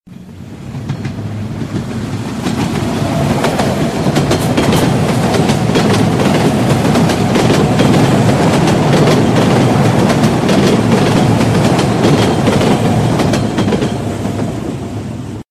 zvuki-poezda_24554.mp3